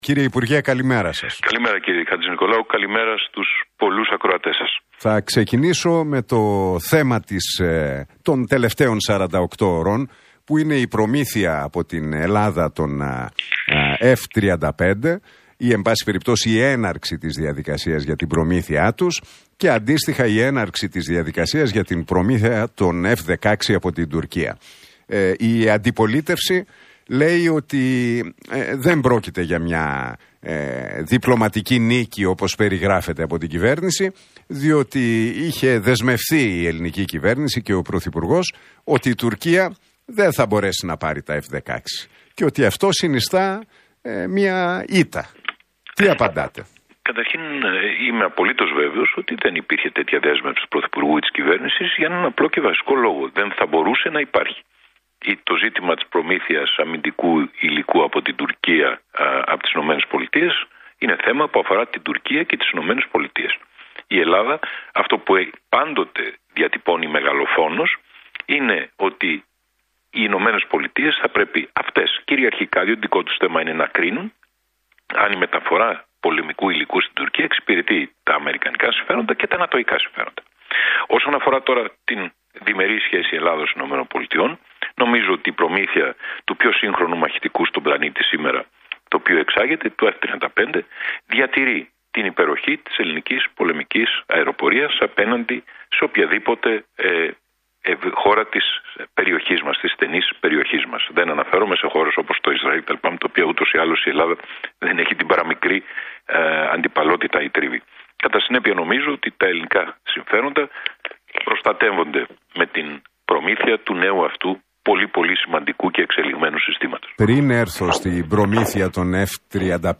Συνέντευξη εφ’ολης της ύλης παραχώρησε στον Realfm 97,8 και τον Νίκο Χατζηνικολάου ο υπουργός Εθνικής Άμυνας Νίκος Δένδιας μιλώντας για το εξοπλιστικό